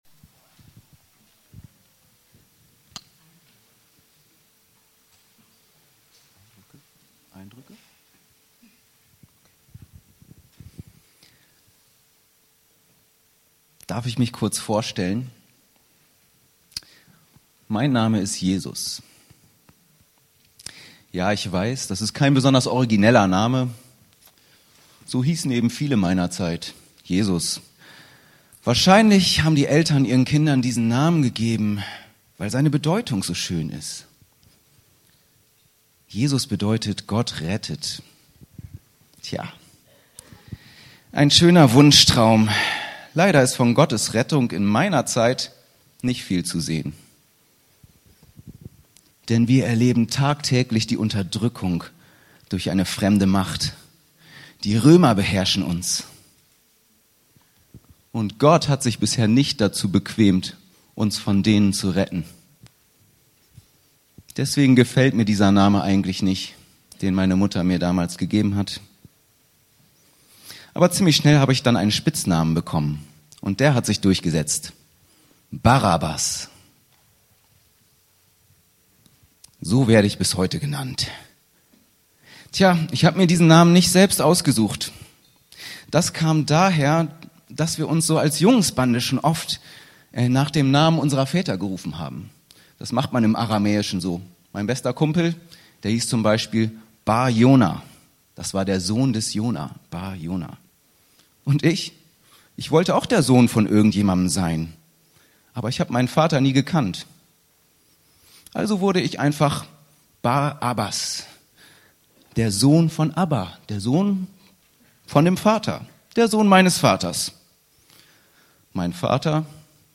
Der große Tausch - Predigt zu Karfreitag